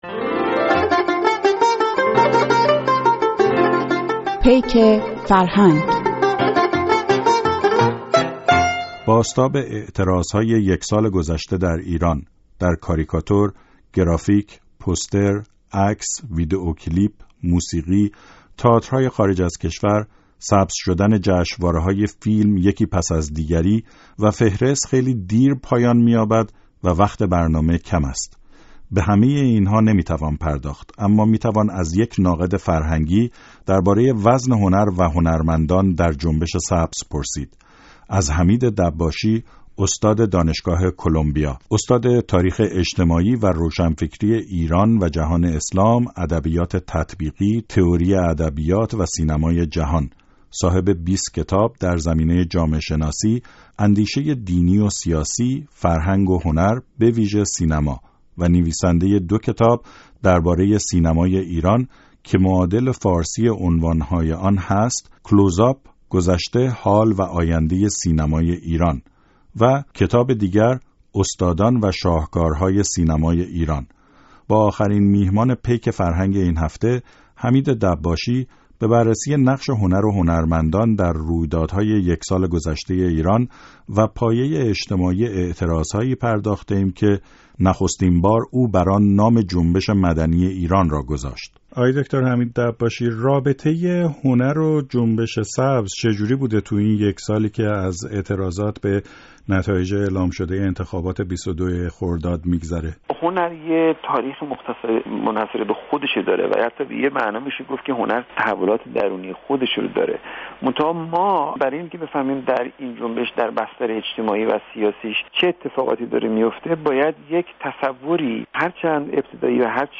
گفت‌وگو با حمید دباشی، استاد دانشگاه کلمبیا، در سومین برنامه پیک فرهنگ